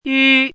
chinese-voice - 汉字语音库
yu1.mp3